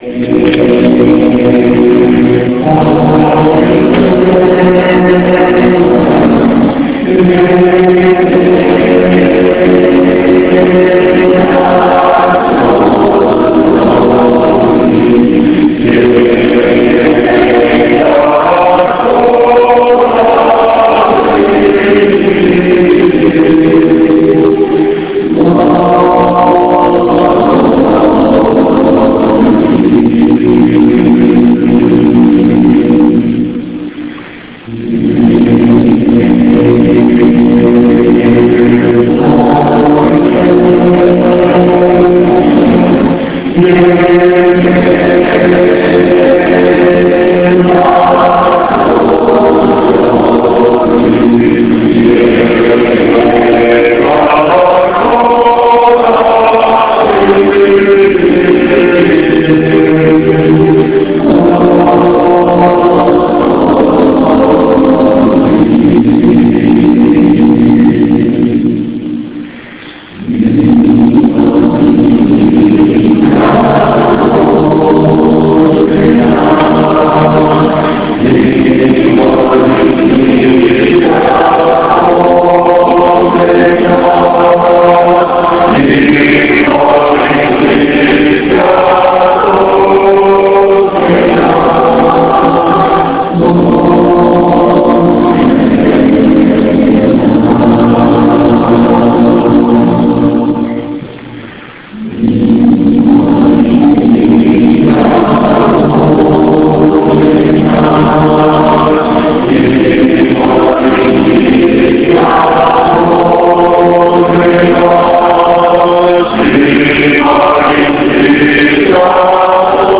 Concert à l'Eglise St Etienne d'Essonnes à Corbeil Essonnes
Les chorales et choeur féminin UAICF de Corbeil-Essonnes ont organisé un concert gratuit à l'église Saint Etienne d'Essonnes.